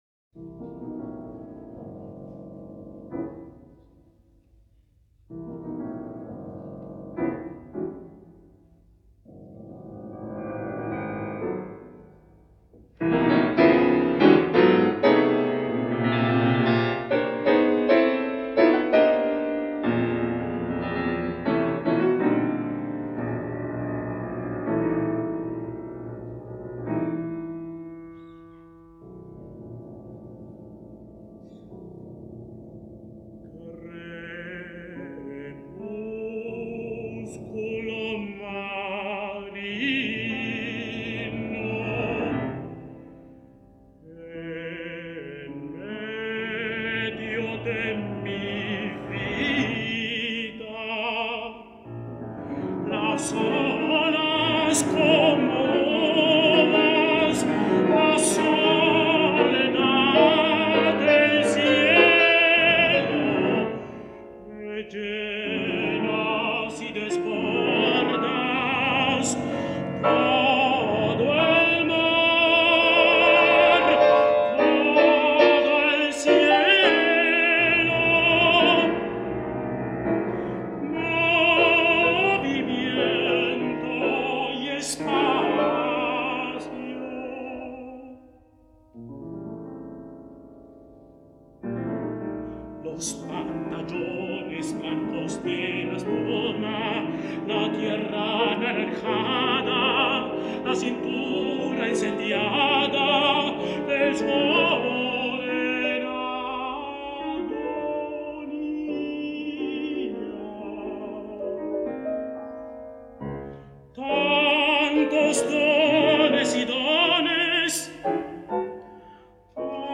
Oda a la Esperanza para tenor y piano